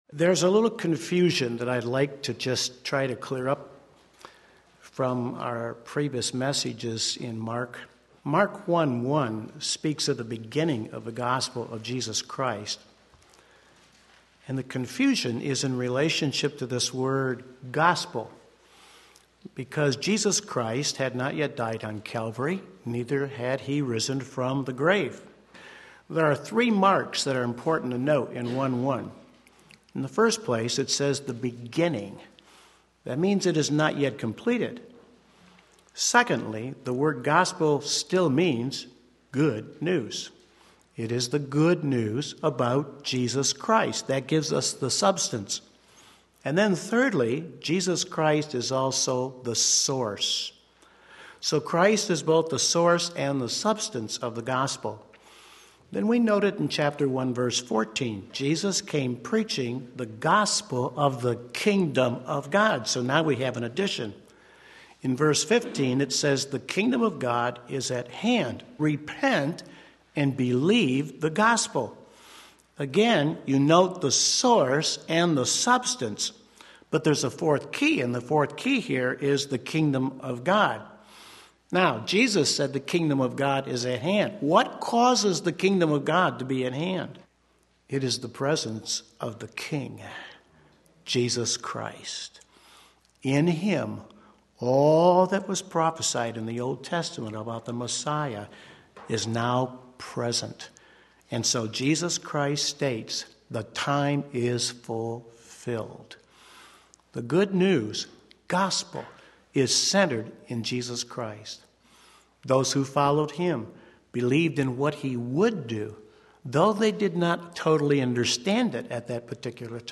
Sermon Link
Challenging Christ’s Ministry Mark 2:13-28 Sunday Morning Service